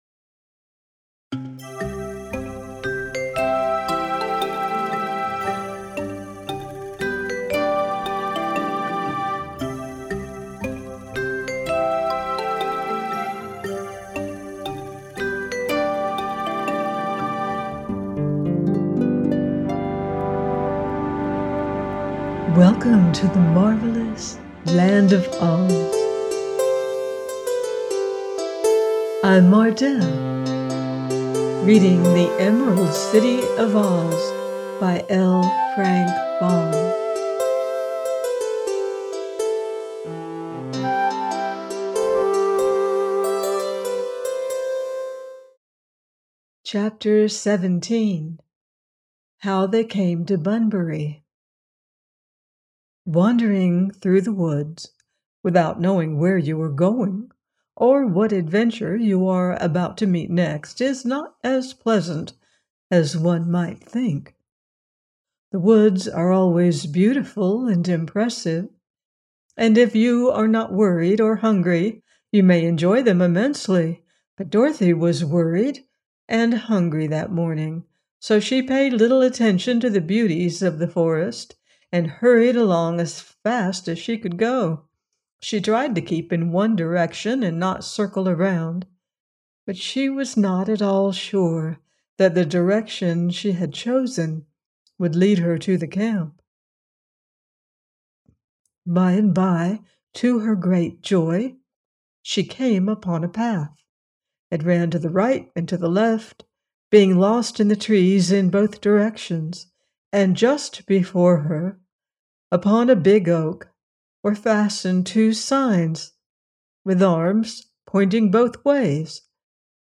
The Emerald City Of OZ – by L. Frank Baum - audiobook